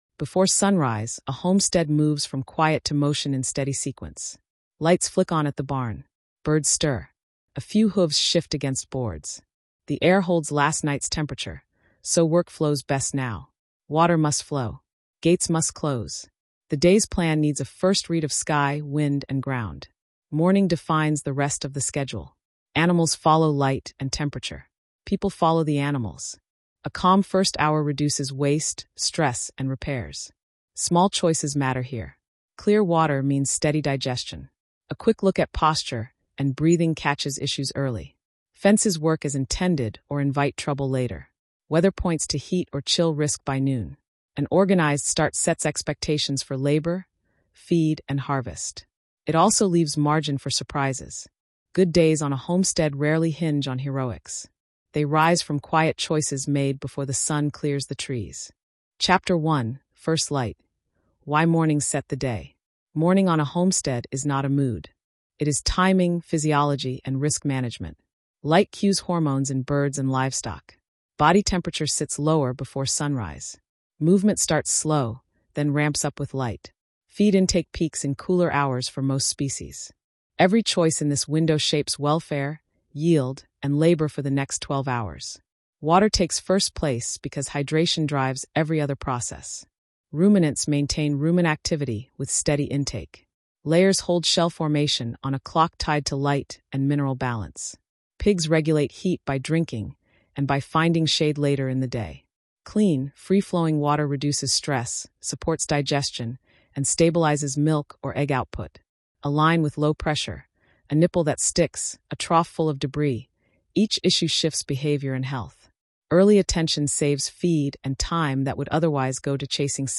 From first light to lights out, this Smithsonian-tone explainer walks the real rhythm of a working homestead. Water, welfare, weather, and timing shape every choice. You see how feed, eggs, milk, soil, tools, heat plans, harvest, storage, and night checks link into one calm, repeatable day.